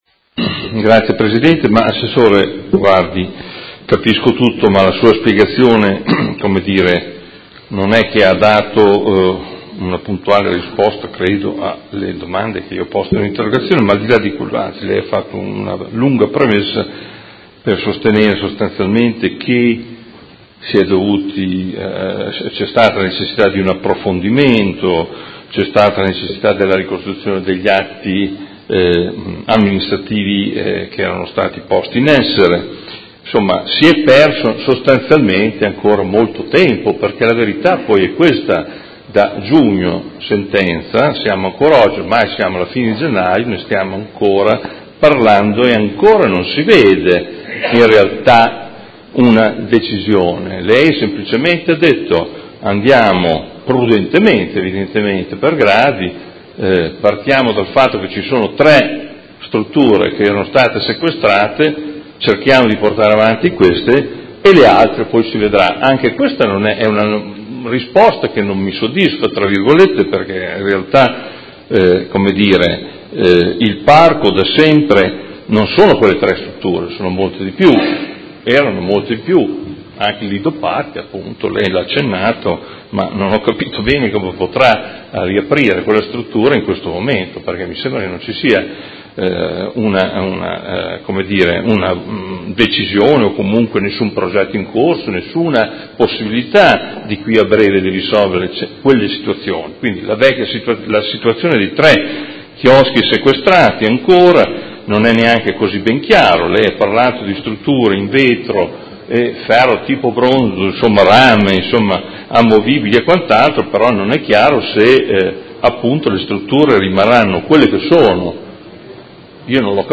Seduta dell'11/01/2018 Replica a risposta Assessore Vandelli. Interrogazione del Consigliere Morandi (FI) avente per oggetto: Dopo la sentenza del Tribunale di Modena che ha assolto i tecnici comunali e liberato dal sequestro i chioschi del Parco delle Rimembranze, questi potranno essere aperti e fornire il servizio utile che tutti i cittadini chiedono?